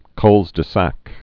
(kŭlzdĭ-săk, klz-)